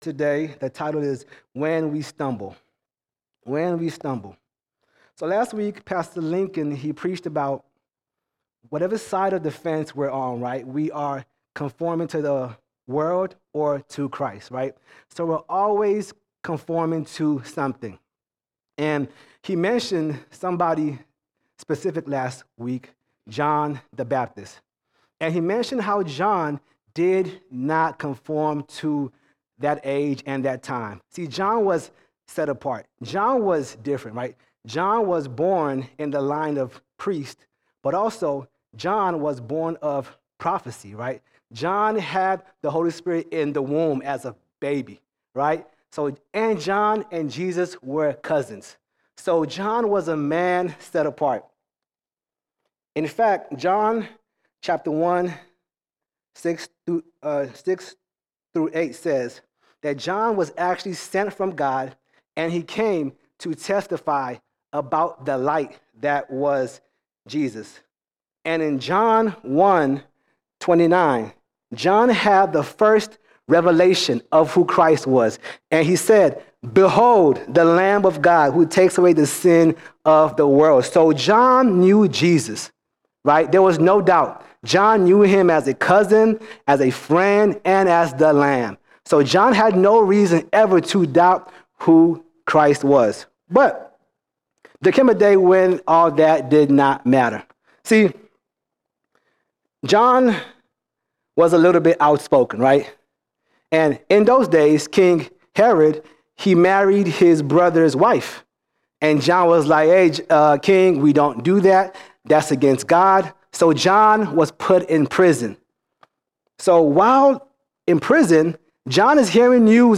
25 November 2024 Series: Sunday Sermons Topic: sin All Sermons When We Stumble When We Stumble We all stumble even as Christians.